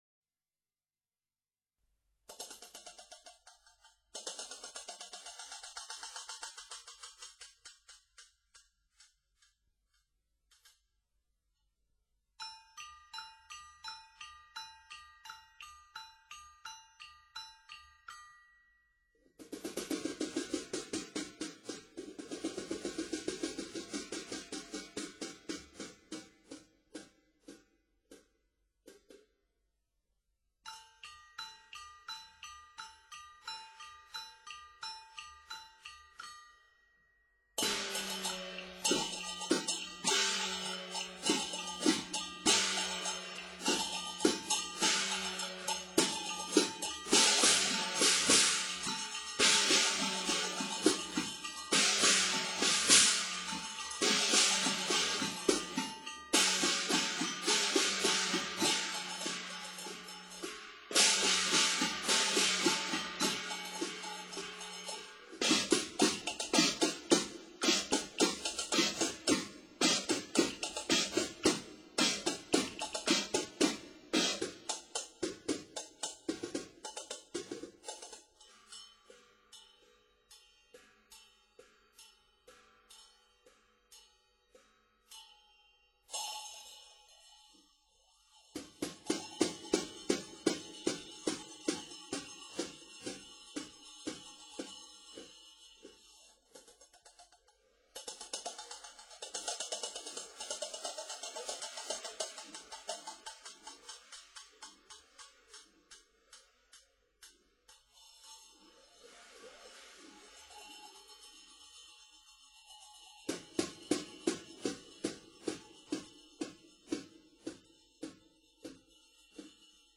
让我们欣赏一段鸭子拌嘴的乐器表演，它取材于西安鼓乐当中的一个套子的开场锣鼓。作者发挥民间打击乐的特点，使用不同的敲击技术，在音色、音量、力度等方面进行变化，对比，展现鸭子拌嘴的生动情景。其中使用的乐器有小钹，大、小木鱼；云锣。